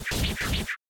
hit1.wav